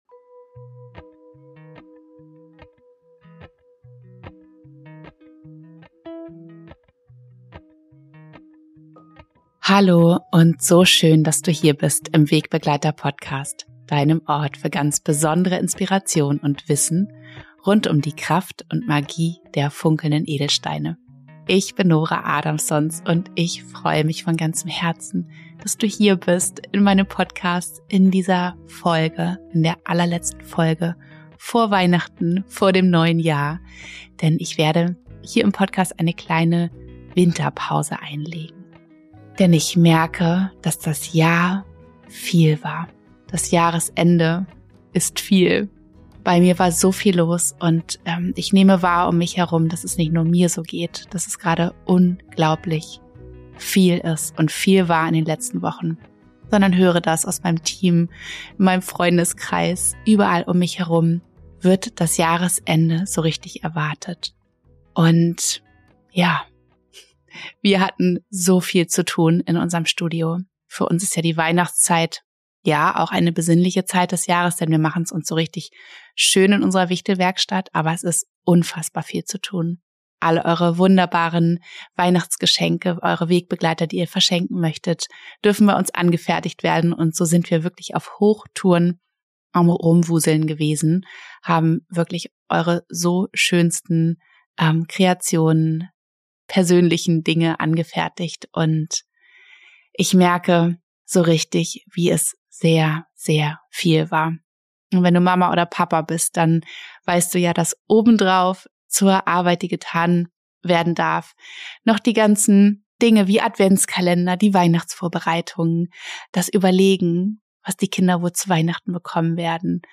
In dieser Weihnachtsfolge, die auch die letzte für dieses Jahr ist, nehme ich dich mit auf eine geführte Meditation, bevor ich eine kleine Winterpause mache.